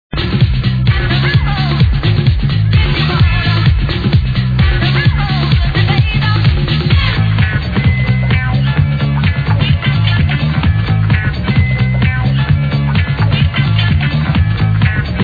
Famous dance/groove tune! you must know this...:)